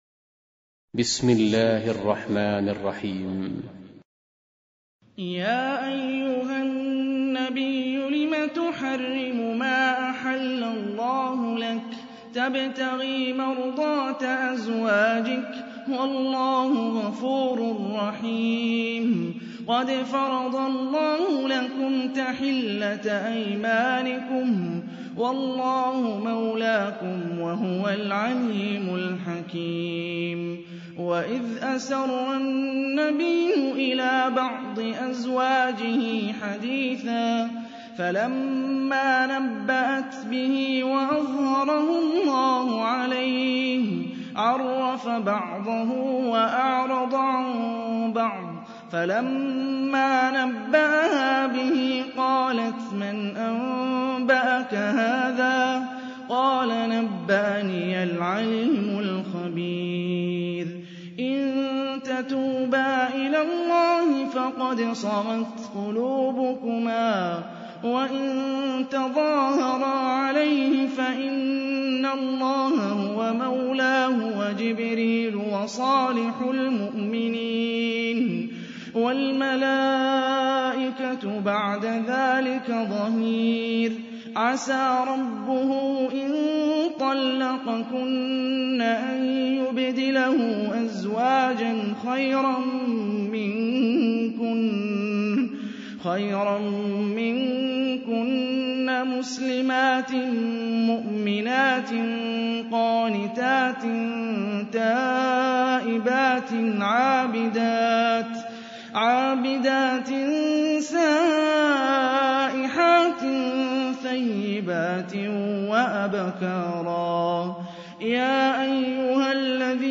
66. Surah At-Tahr�m سورة التحريم Audio Quran Tarteel Recitation
Surah Repeating تكرار السورة Download Surah حمّل السورة Reciting Murattalah Audio for 66.